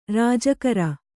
♪ rāja kara